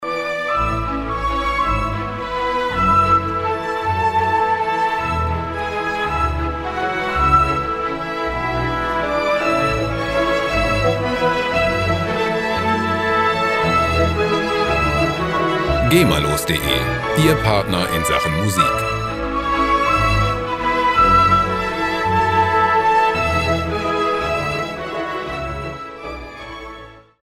Gema-freie Klassik Loops
Musikstil: Klassik
Tempo: 163 bpm